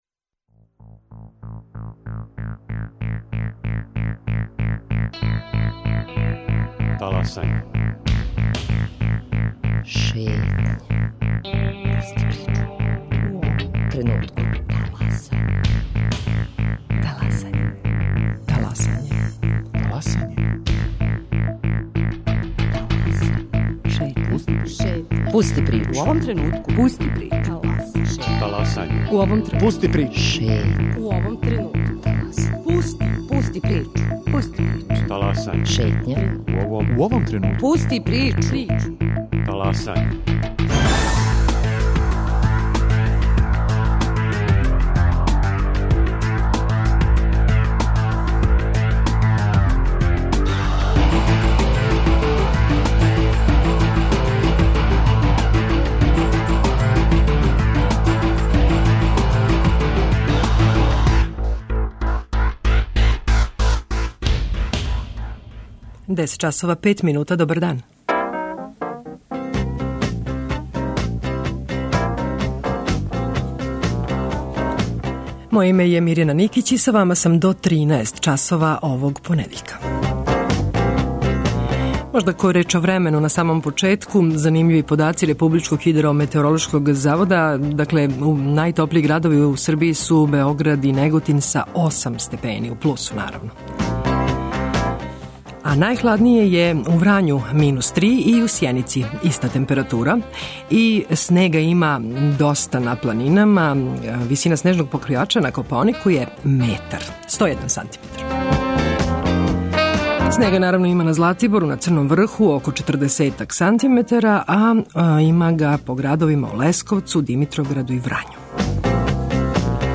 Од колега из локалних радио станица чућемо како ових дана живе грађани Врања, Лесковца и Бора. Сазнаћете како се боре са снежним падавинама и шта се још догађа у овим градовима и околини.